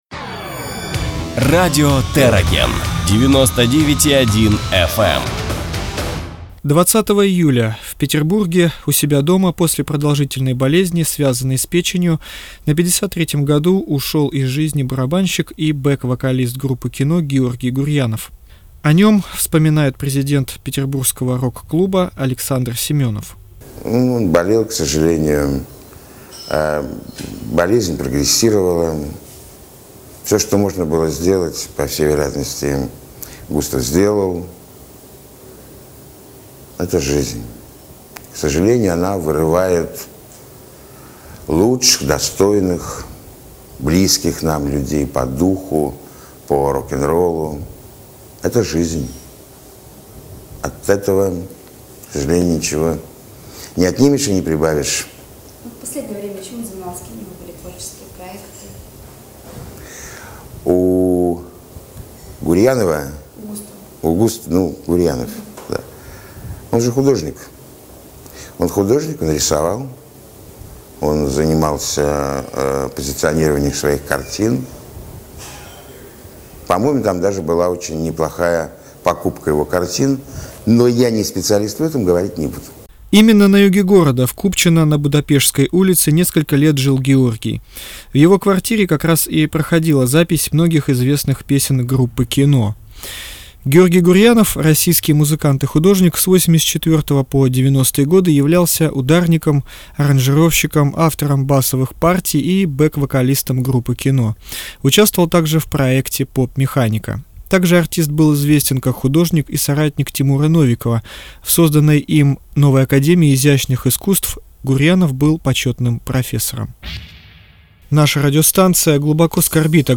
Радиостанция Terragen в прямом эфире сообщает о смерти Георгия Гурьянова.
В память о Георгии в эфире звучит песня группы "Кино"- Сказка.